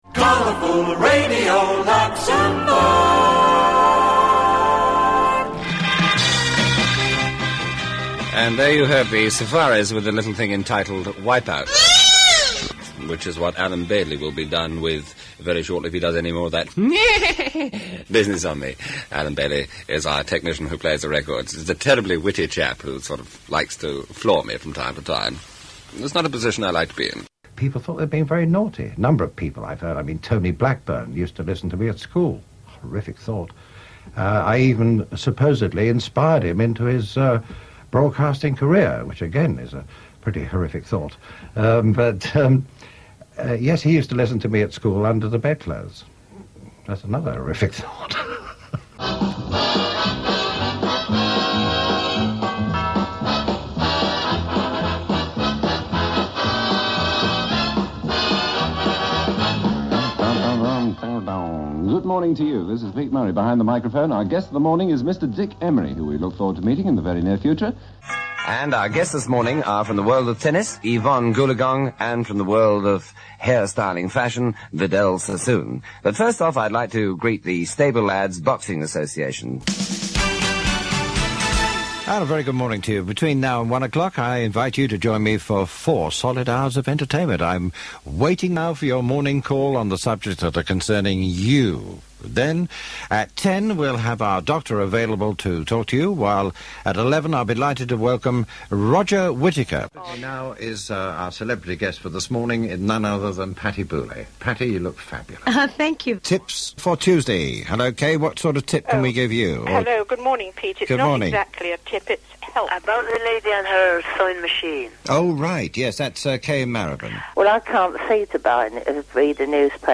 The sound of Radio 2 for a generation, back in those cardigan days when shows had sig tunes; and his ding-dong ‘Open House ‘ theme was instantly recognised.